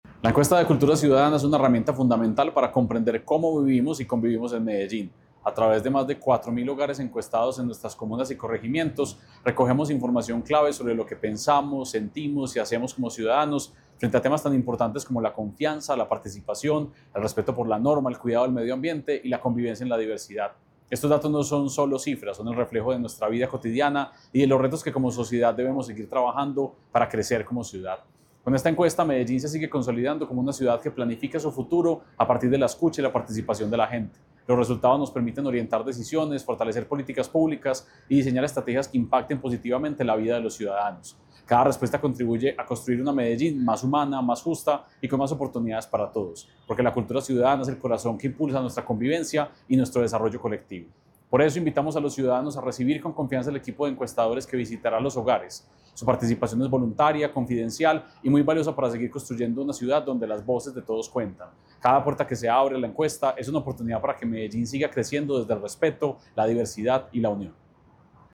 Declaraciones secretario de Cultura Ciudadana, Santiago Silva Jaramillo
Declaraciones-secretario-de-Cultura-Ciudadana-Santiago-Silva-Jaramillo-5.mp3